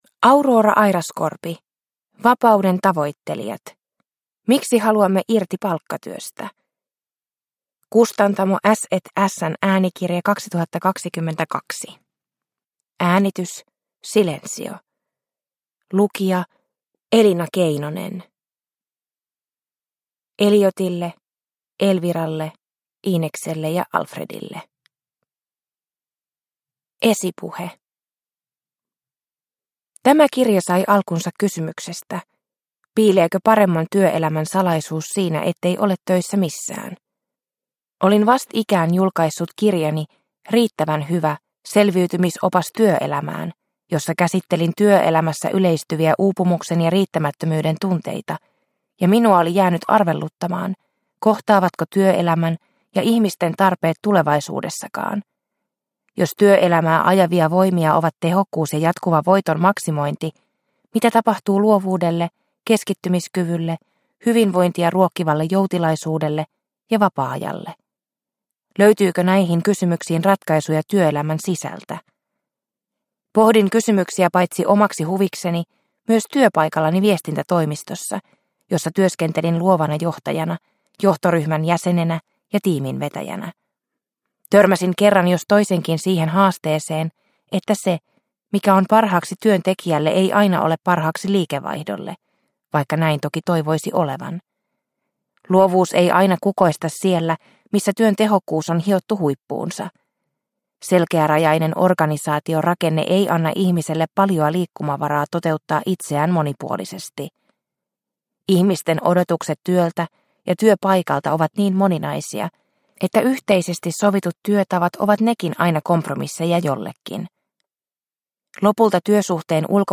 Vapauden tavoittelijat – Ljudbok – Laddas ner